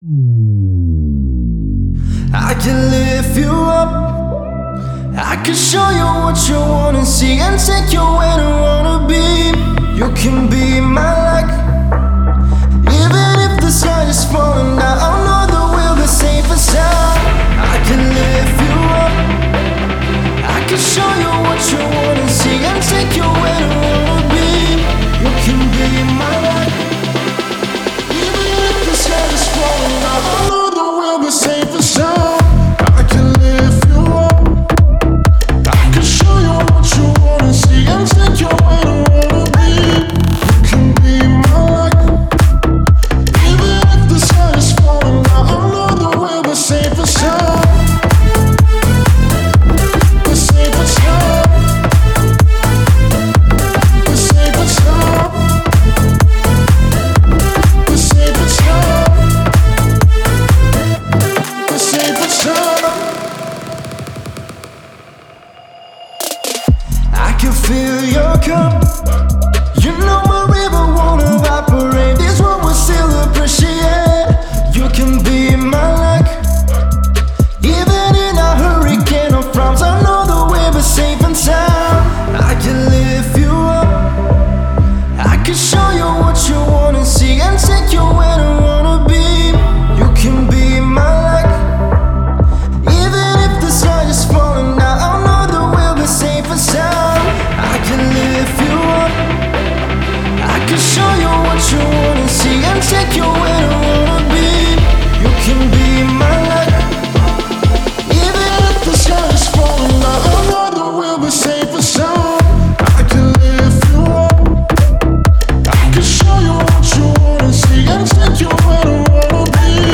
это энергичная трек в жанре EDM